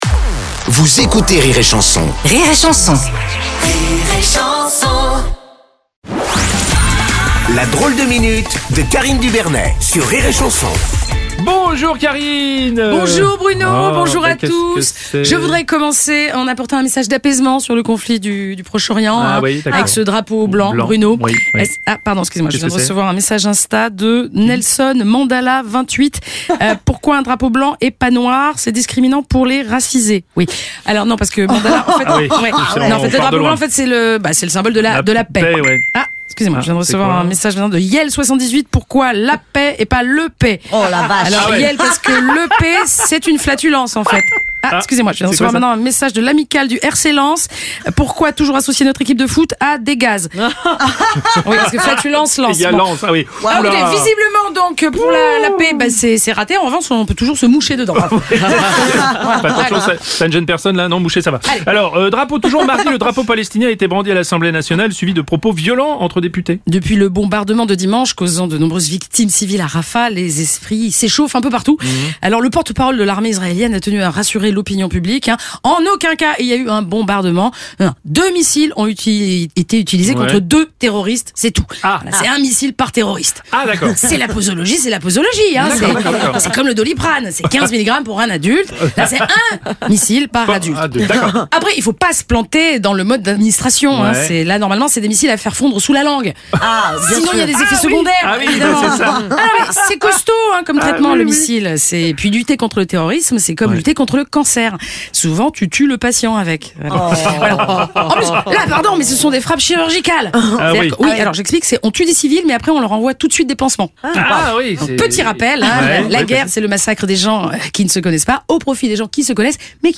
L'actu vue par Karine Dubernet - Tous les vendredis à 7h45 et 9h45 dans le Morning du rire avec Bruno Roblès sur Rire & Chansons